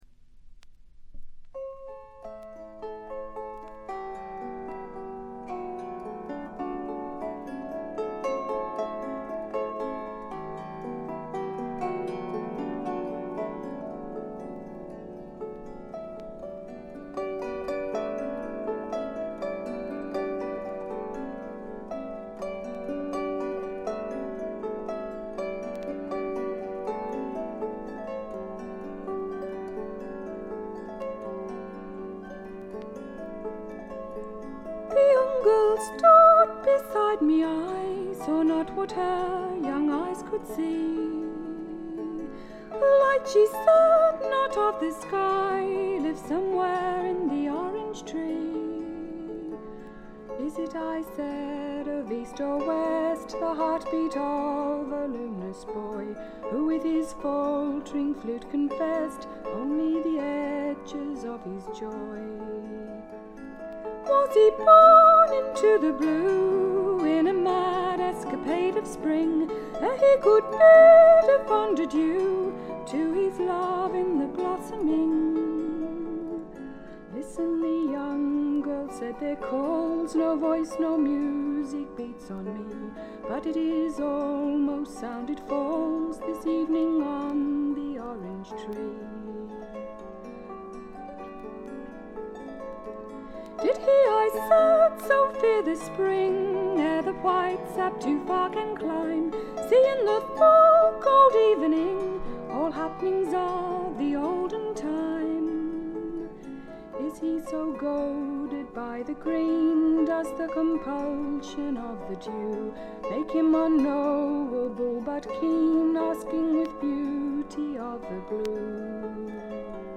トラディショナル・ソングとトラッド風味の自作曲を、この上なく美しく演奏しています。
試聴曲は現品からの取り込み音源です。
vocal, harp, banjo
viola.